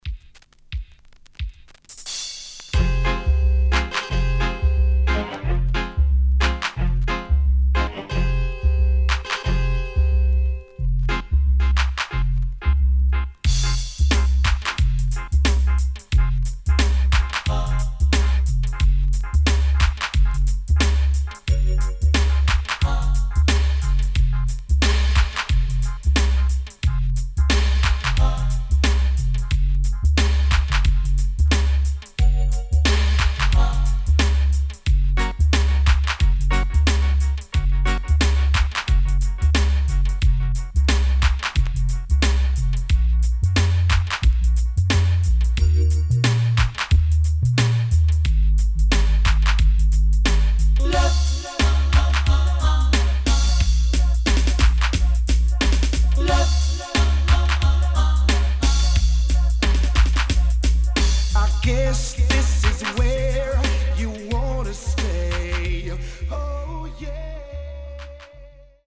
riddim
【12inch】
SIDE A:少しチリノイズ入りますが良好です。